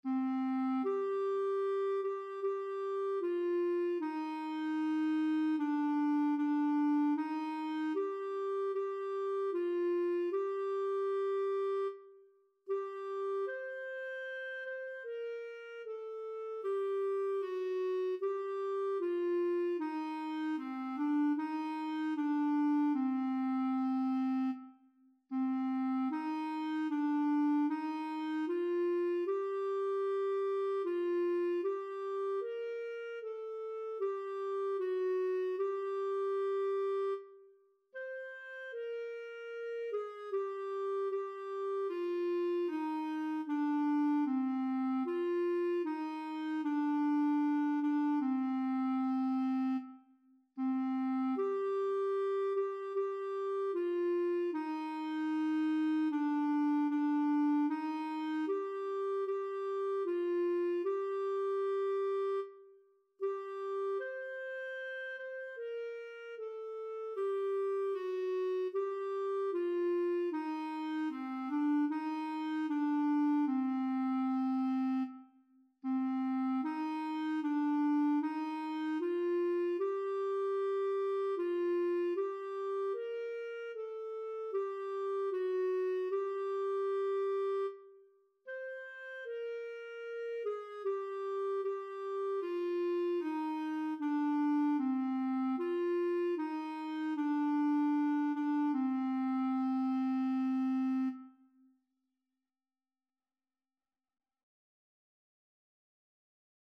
Title: Maria lacrimosa Composer: Anonymous (Traditional) Lyricist: Number of voices: 3vv Voicing: TTB Genre: Sacred, Lamentation
Language: Portuguese Instruments: Organ